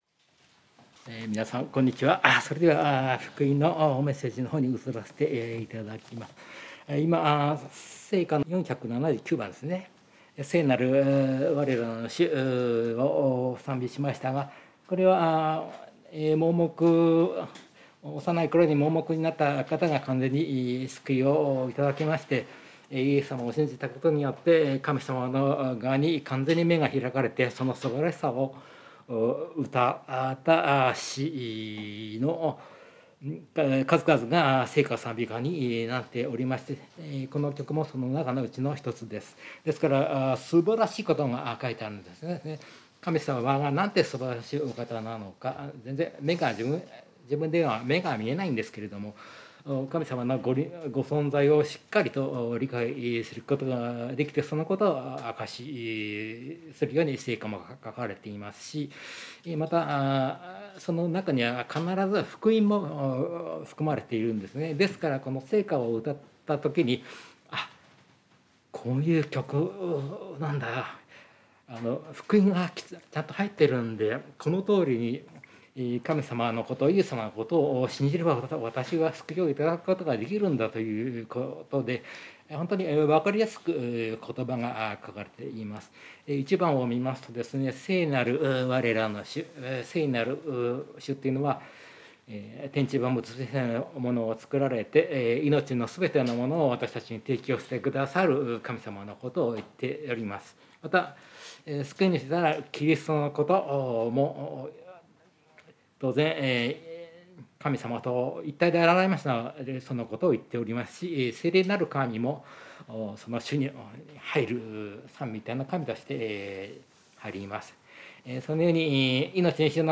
聖書メッセージ No.293